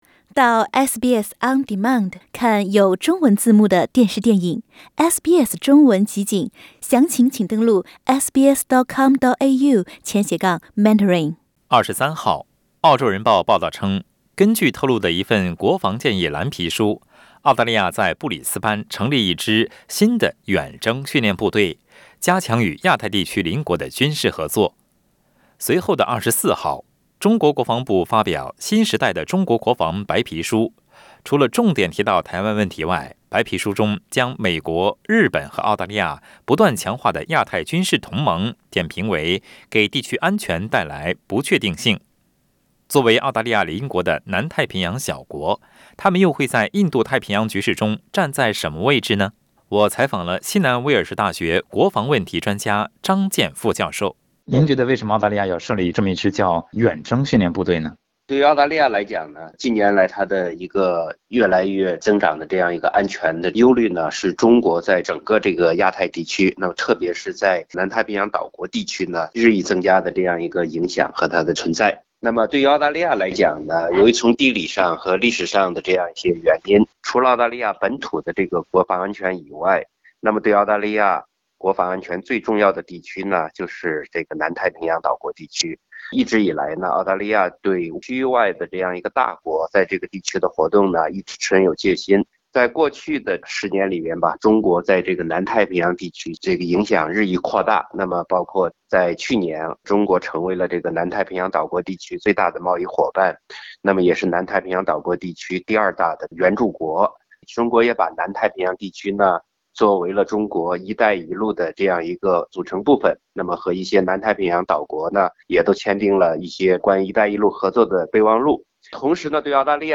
请您点击收听详细的采访内容。